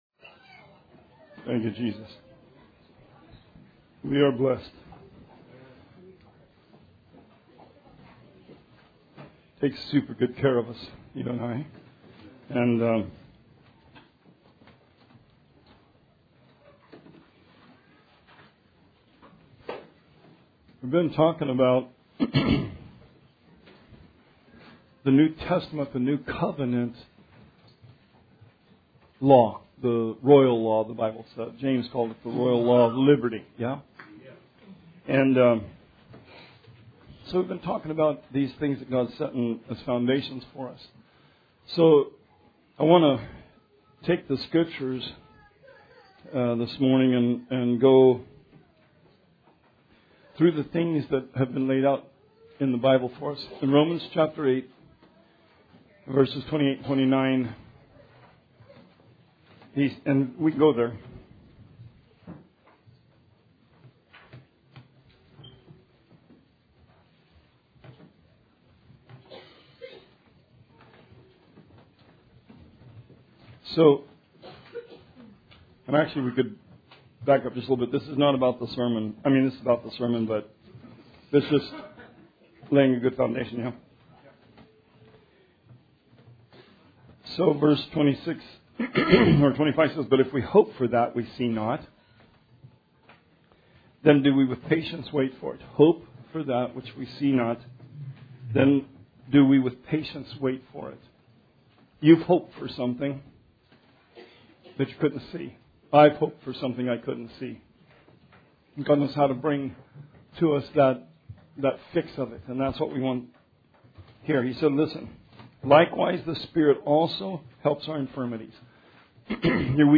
Sermon 9/1/19